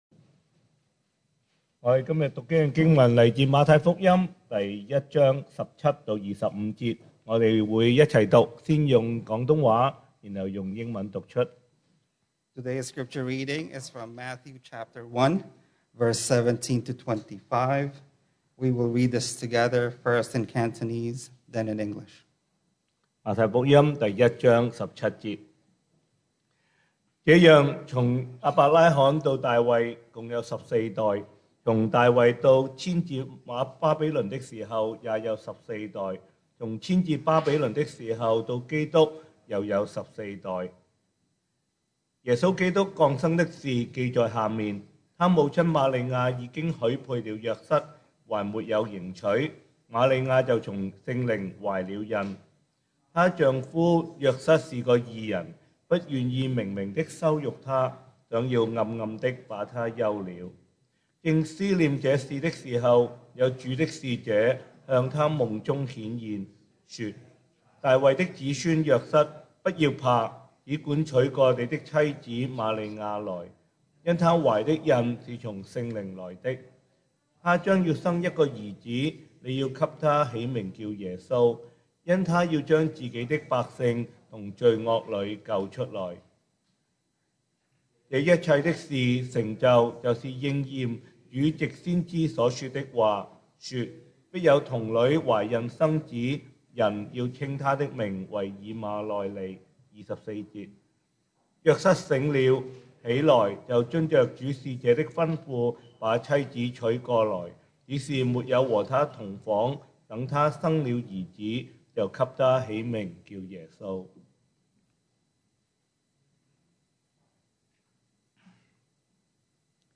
2021 sermon audios
Service Type: Sunday Morning